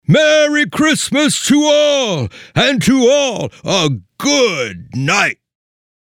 SANTA – MERRY CHRISTMAS TO ALL
SANTA-MERRY-CHRISTMAS-TO-ALL.mp3